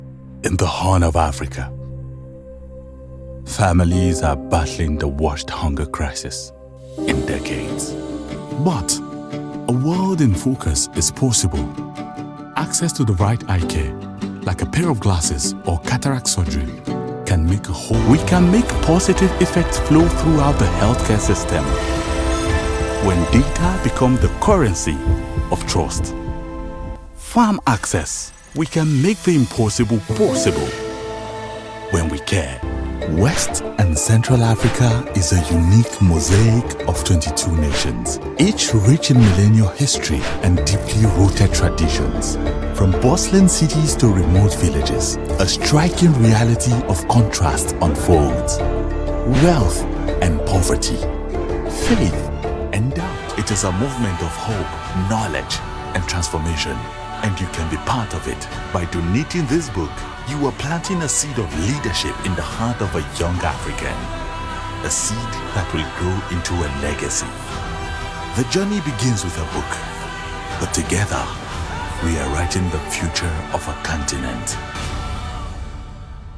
a deep, warm baritone voice with authentic African and Nigerian accents
Narration
I specialize in recording voiceovers with authentic African accents, including West African and Sub saharan African accent.
Operating from my fully equipped and acoustically treated home studio, I guarantee prompt turnaround times and a flexible revision policy to ensure the smooth progression of your projects.
Fully soundproofed booth
BaritoneBassDeep